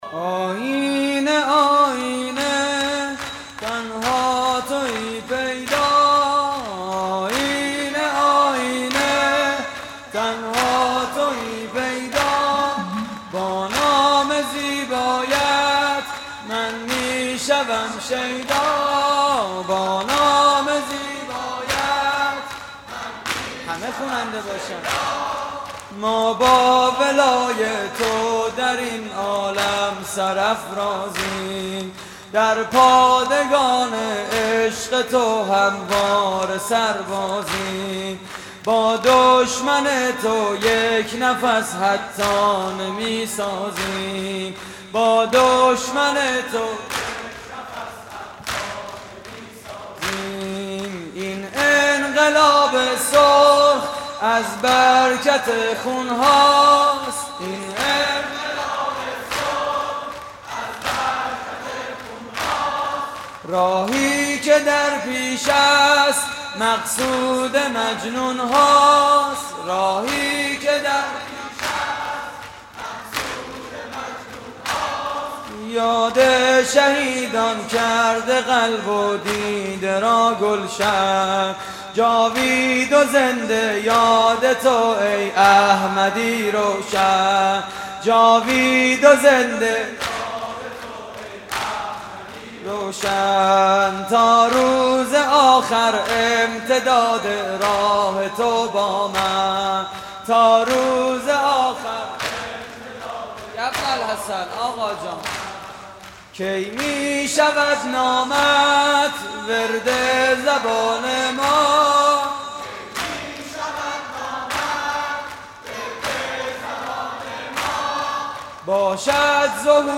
شور: تنها تویی پیدا
مراسم عزاداری ظهر تاسوعای حسینی